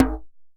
DJEM.HIT03.wav